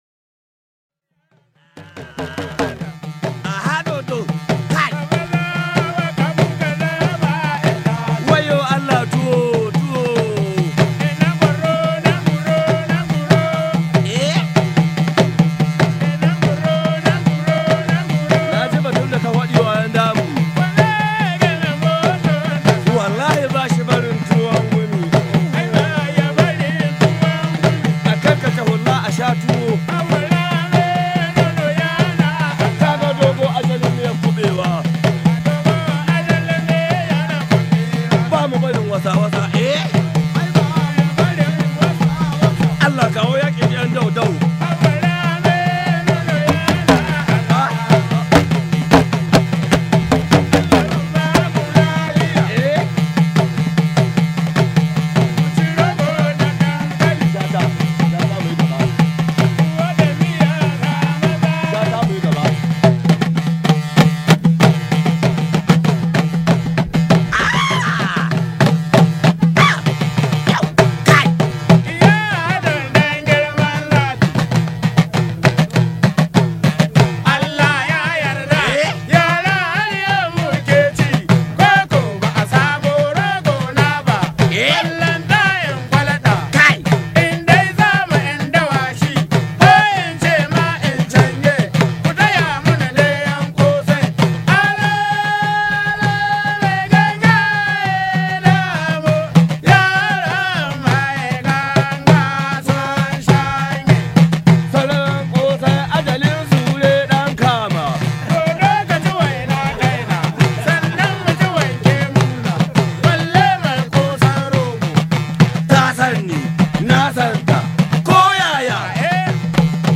It’s from Niger Republic,
drumming and singing.